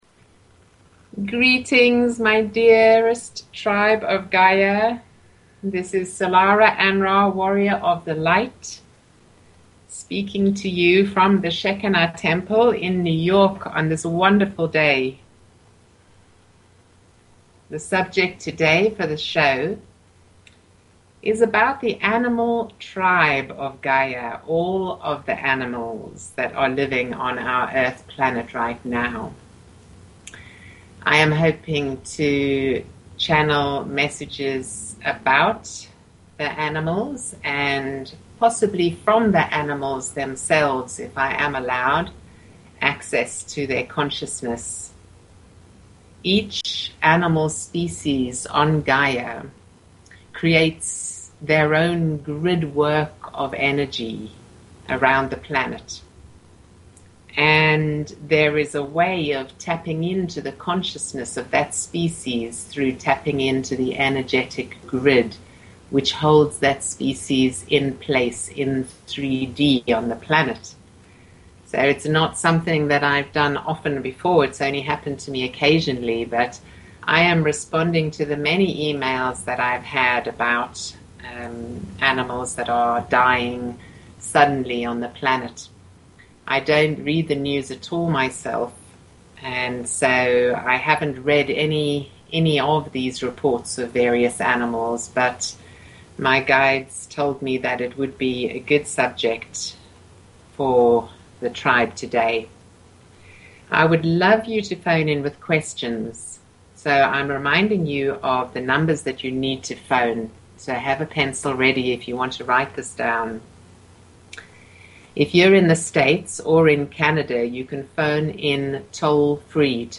Talk Show Episode, Audio Podcast, Illumination_from_the_Councils_of_Light and Courtesy of BBS Radio on , show guests , about , categorized as